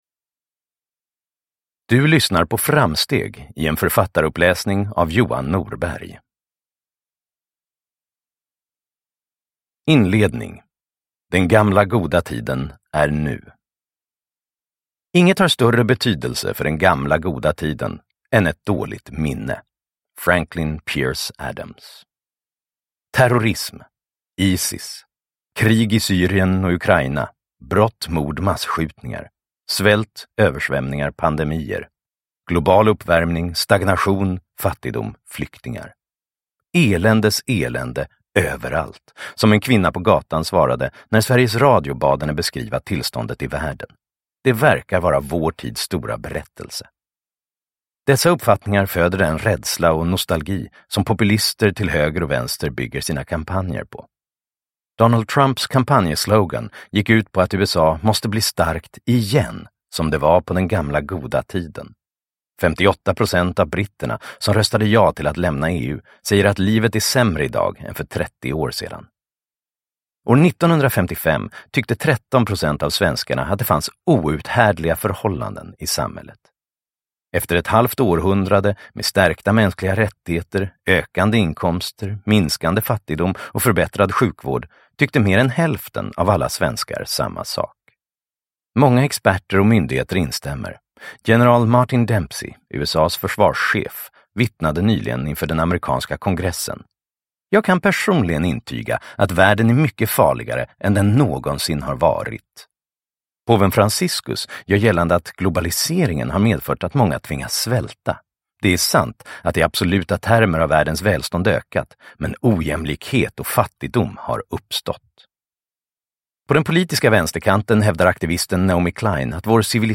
Framsteg: Tio skäl att se fram emot framtiden (ljudbok) av Johan Norberg